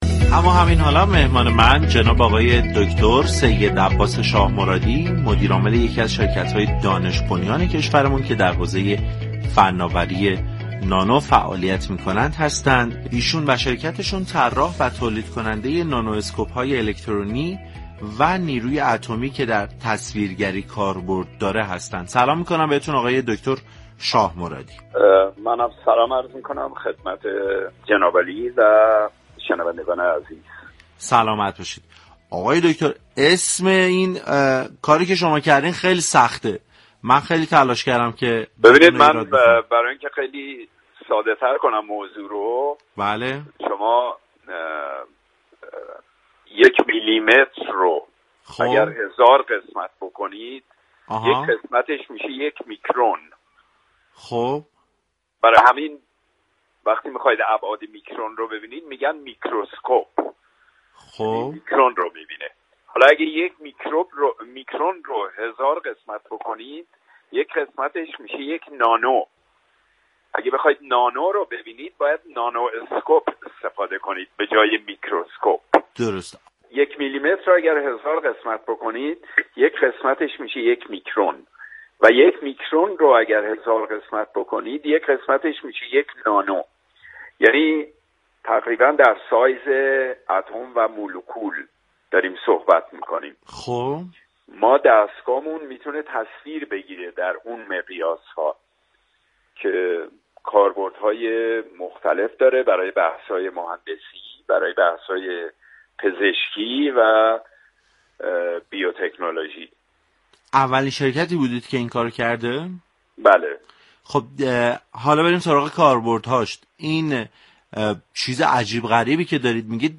در گفت و گو با «علم بهتر است»